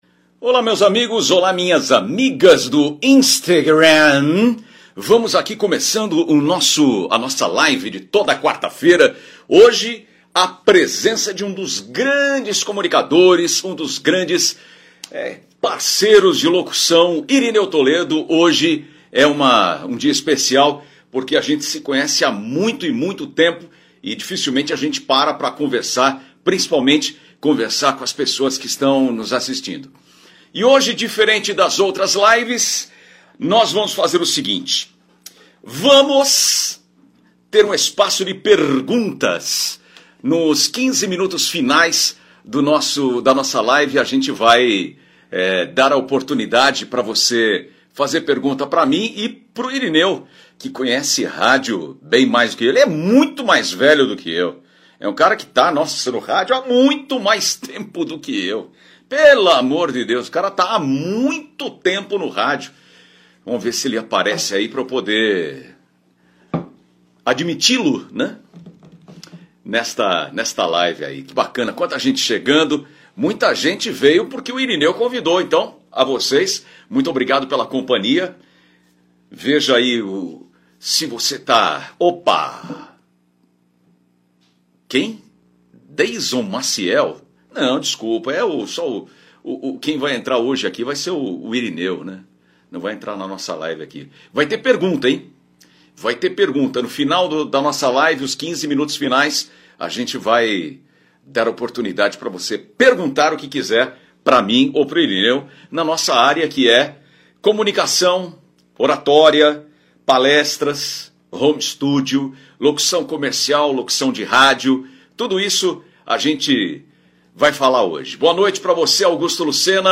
156-feliz-dia-novo-entrevista.mp3